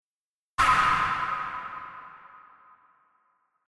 破裂音（パァンッ！！！みたいなやつ。）
THE加工音みたいなやつ。
などなど最初の音がでかくてなおかつ音の長さ的には短い音。